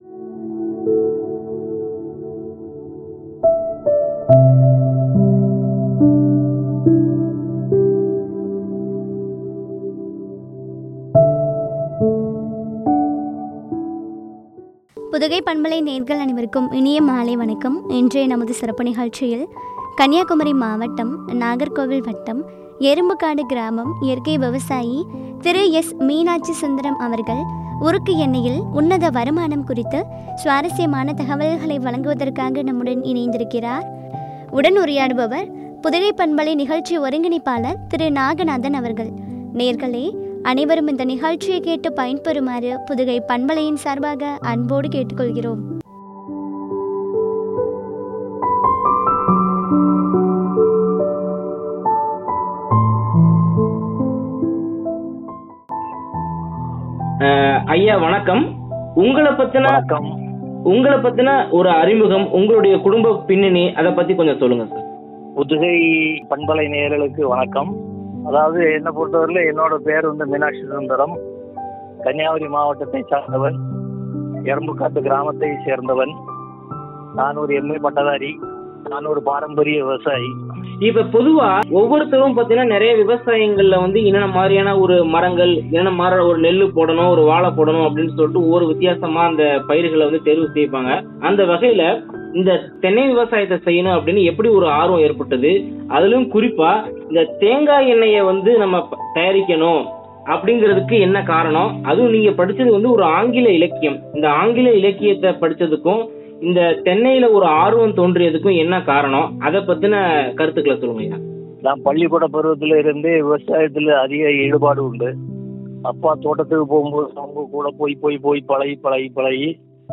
” உருக்கு எண்ணெயில் உன்னத வருமானம்” குறித்து வழங்கிய உரையாடல்.